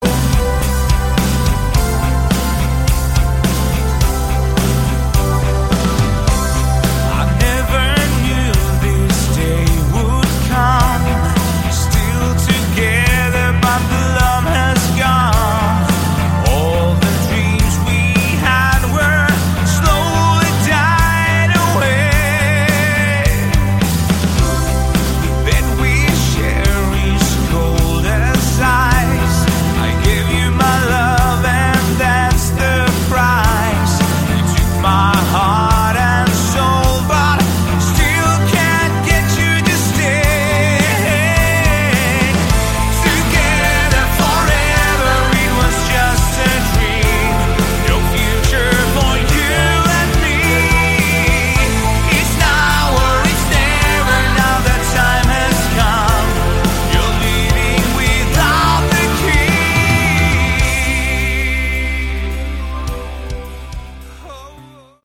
Category: Melodic Rock
drums, vst-bass, keyboards and backing vocals
lead and backing vocals
guitars